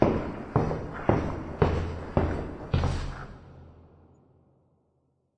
走在碎石上
描述：走在石渣足迹在一个晚夏夜期间在科罗拉多
Tag: 脚步 碎石 台阶 步骤 足迹